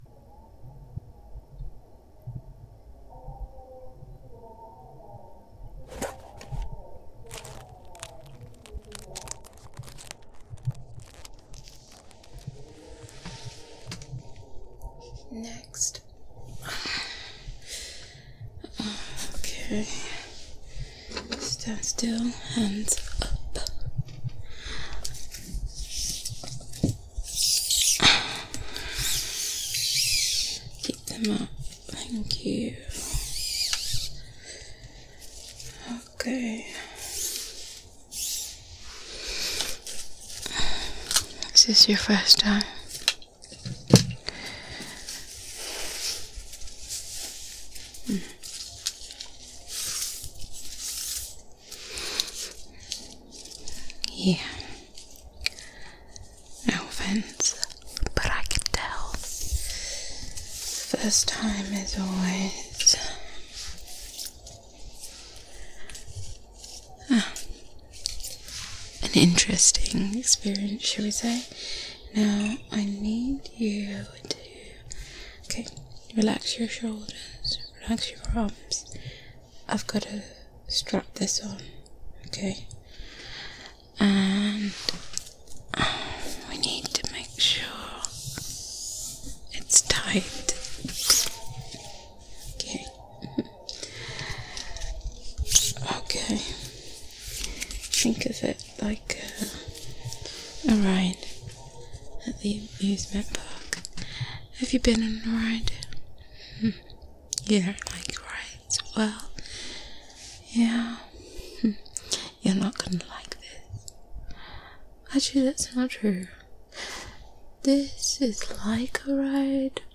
stargate-asmr-rp.mp3